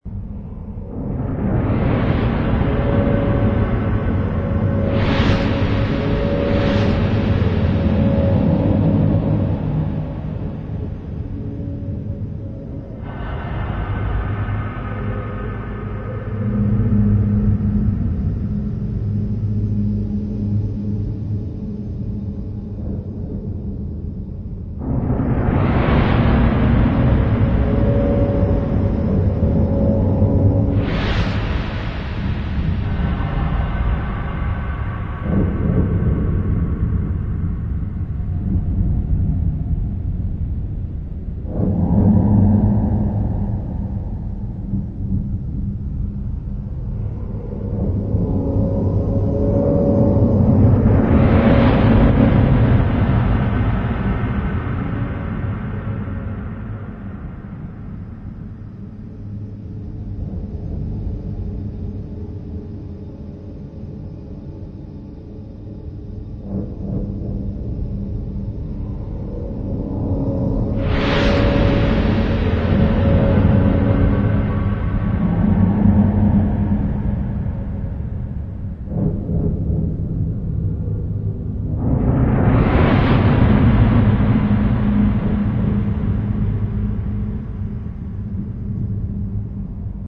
AMBIENCES